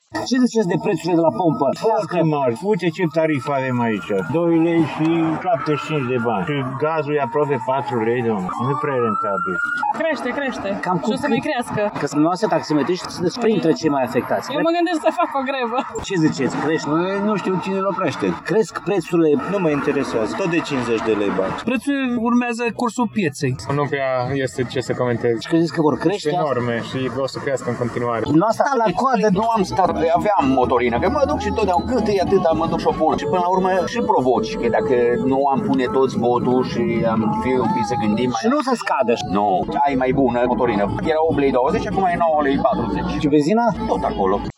În ciuda aglomerației creată brusc aseară ca urmare a saltului prețului la pompele de carburanți, nu toți șoferii din Tg. Mureș s-au înghesuit să se aprovizioneze: